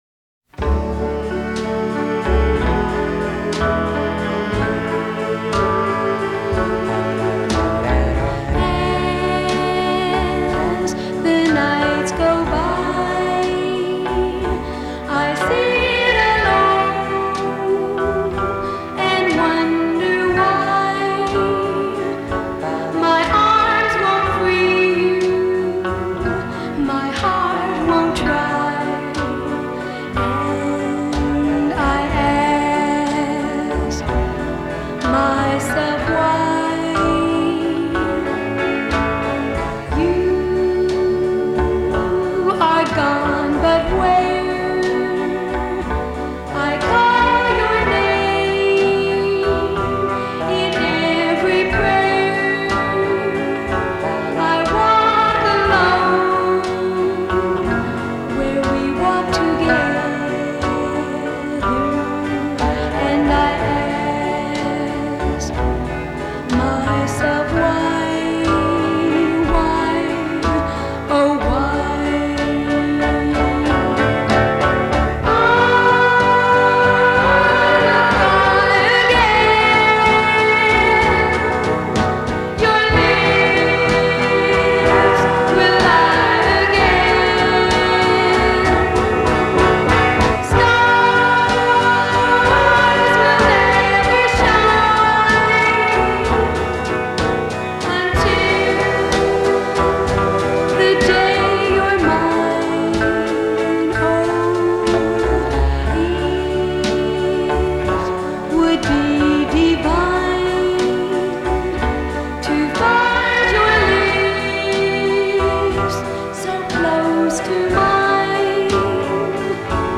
stereo version